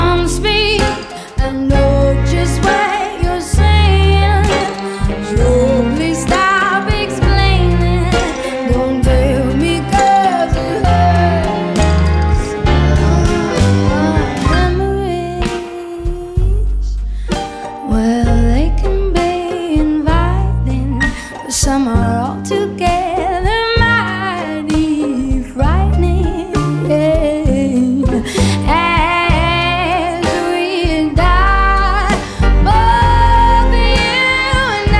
Жанр: Поп музыка / Джаз